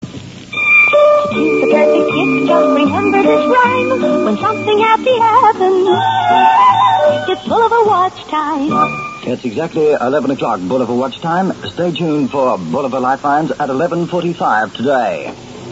Bulova watch advert.mp3